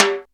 Good Tome Drum Sample G Key 03.wav
Royality free tom drum single hit tuned to the G note. Loudest frequency: 2596Hz
good-tome-drum-sample-g-key-03-BBK.mp3